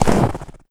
High Quality Footsteps
STEPS Snow, Run 25-dithered.wav